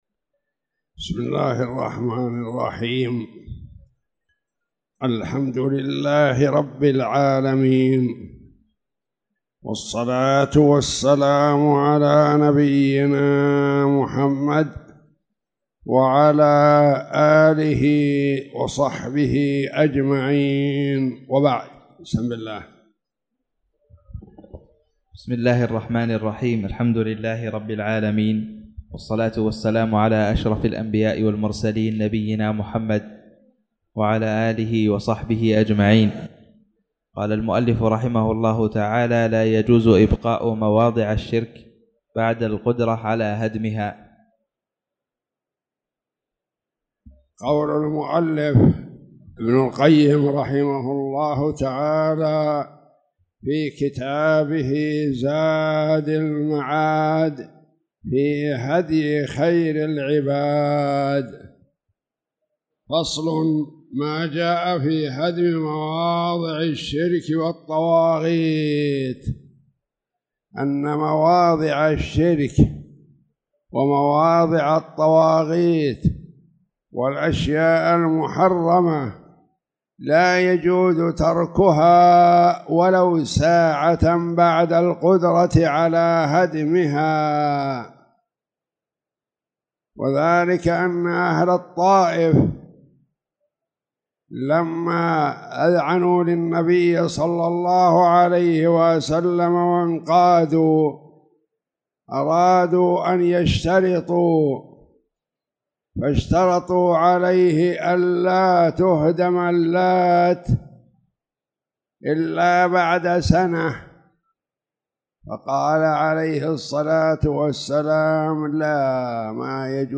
تاريخ النشر ١٣ شعبان ١٤٣٧ هـ المكان: المسجد الحرام الشيخ